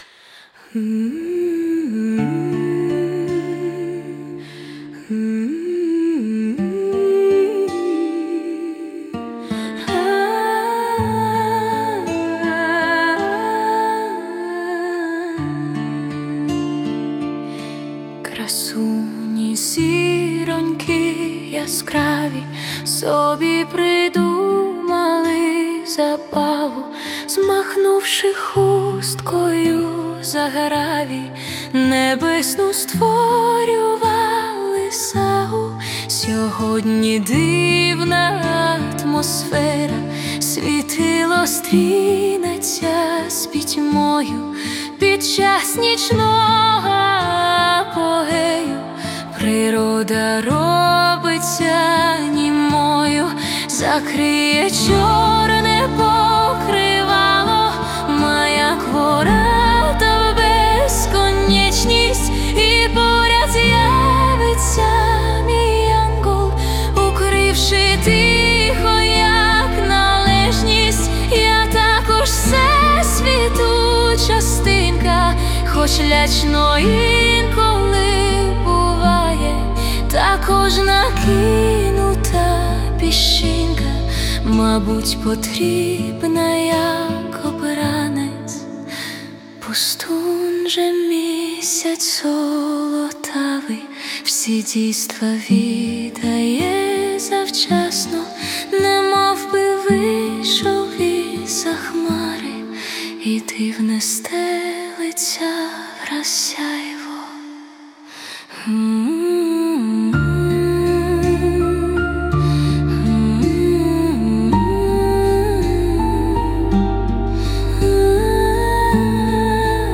Музична композиція створена за допомогою SUNO AI
Неймовірно гарна, ніжна мелодія, на слова чудової небесної лірики .
Дуже гарна і трепетна пісня, бринить як дзвіночки.